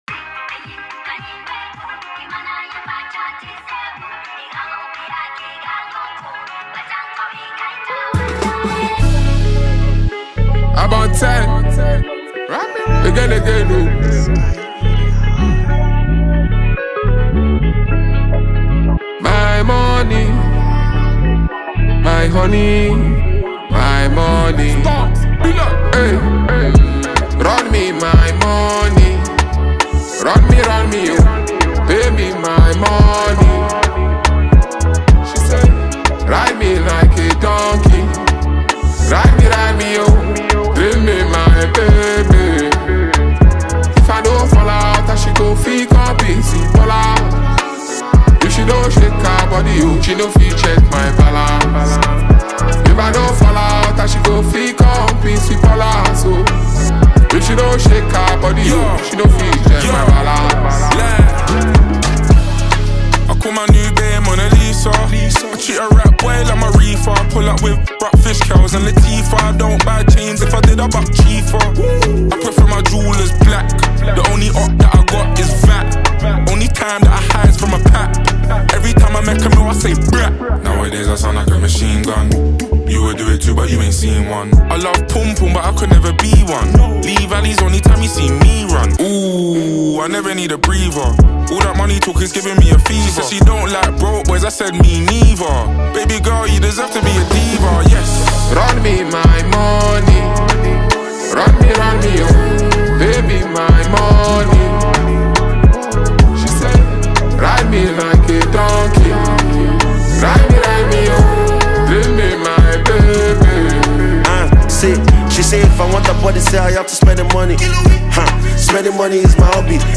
the track delivers nothing short of pure intensity.
creating a perfect blend for both street and club vibes.